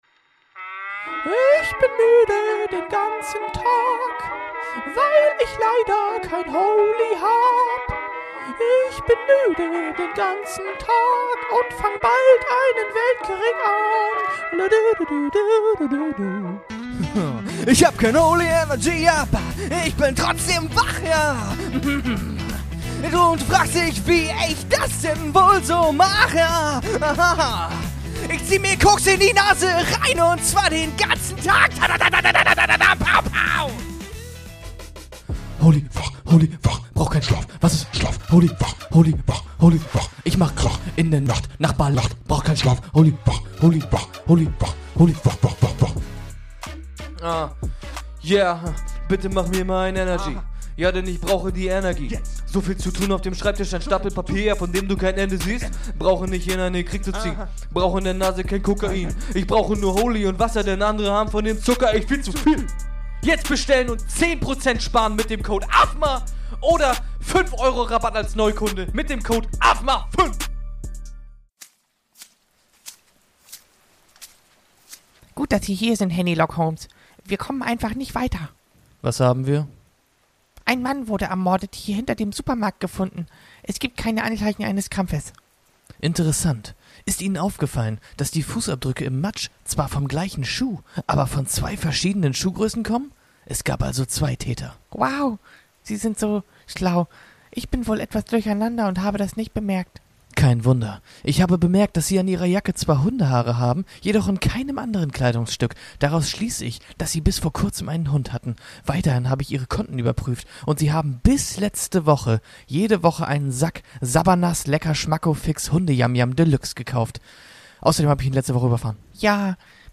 Zwei Jungs, ein Dreamteam!